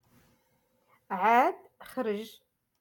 Moroccan Dialect-Rotation five-Lesson Sixty